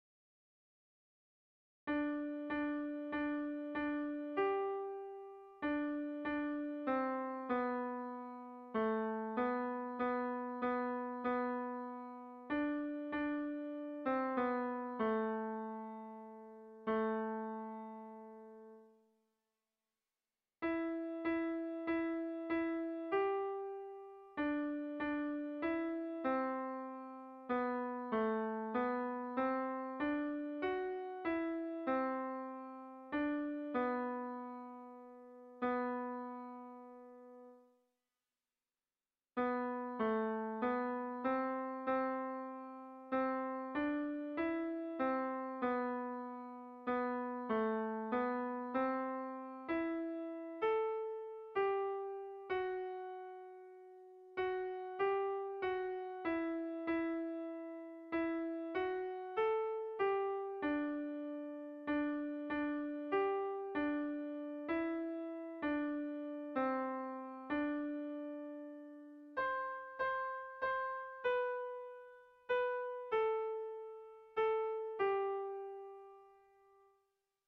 Zorioneko batasunean - Air de bertsos - BDB.
Sentimenduzkoa
Zortziko handia (hg) / Lau puntuko handia (ip)
ABDE